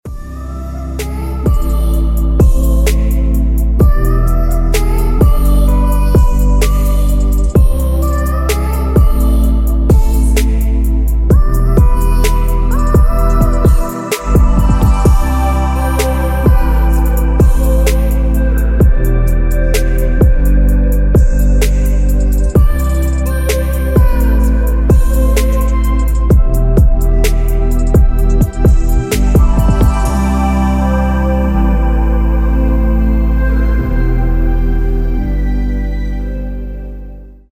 Гарні Мелодії на Дзвінок